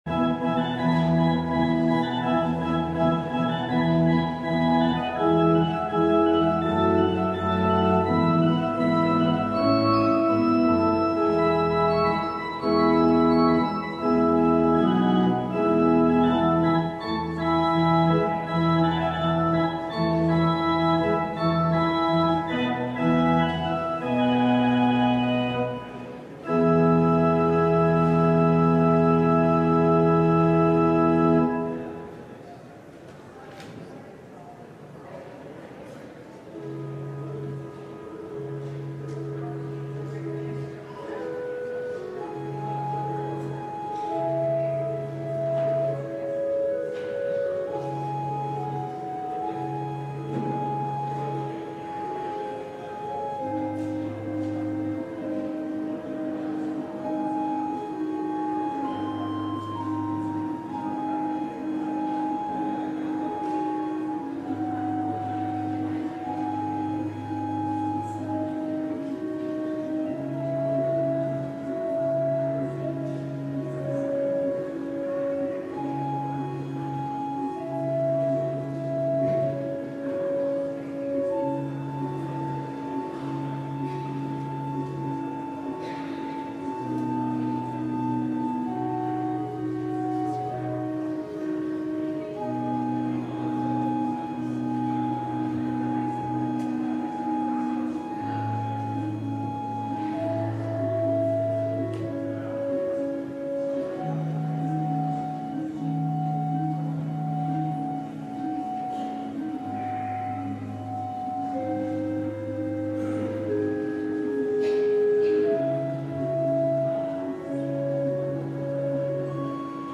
LIVE Midday Worship Service - Being Transformed